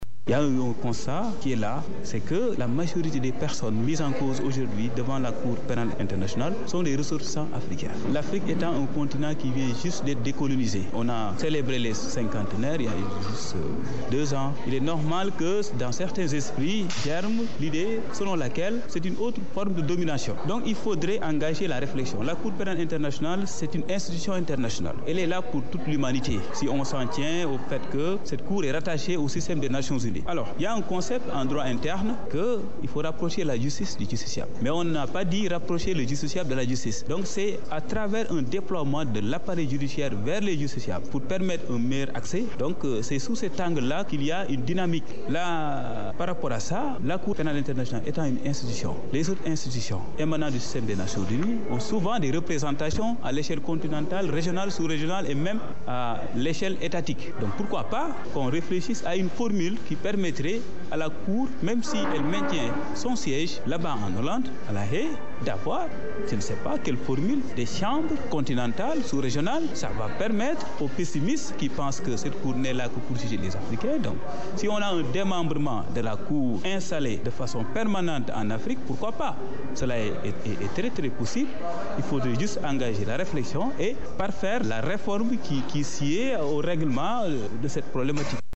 Il pense que cela l’avantage de changer la perception selon laquelle seuls les ressortissants africains comparaissent cette juridiction. Il participait à un panel à l’Université Cheikh Anta Diop (Ucad) de Dakar.